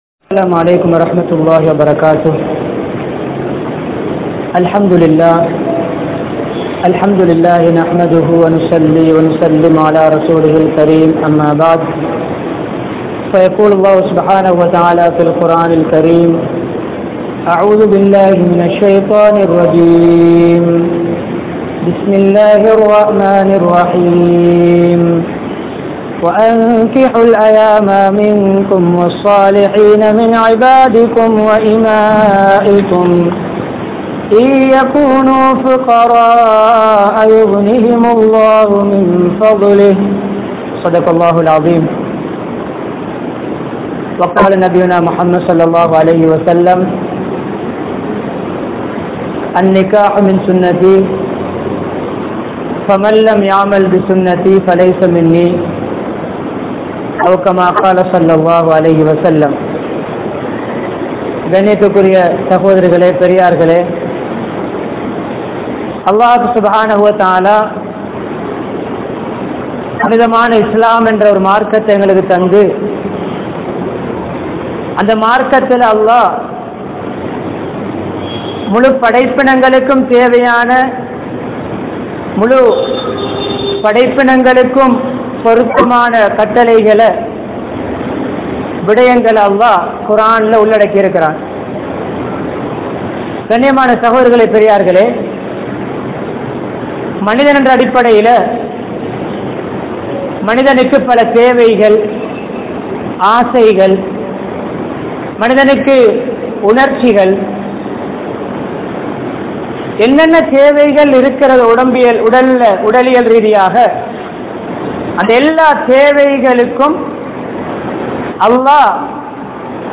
Manaiviyai Purinthu Nadavungal(மனைவியை புரிந்து நடவுங்கள்) | Audio Bayans | All Ceylon Muslim Youth Community | Addalaichenai
Kandauda Jumua Masjidh